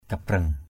/ka-brʌŋ/ 1. (đg.) vểnh = dresser, dressé. tangi kabreng tz} kb$ tai vểnh = les oreilles dressées. asaih kabreng tangi a=sH kb$ tz} ngựa vểnh tai = le cheval dresse...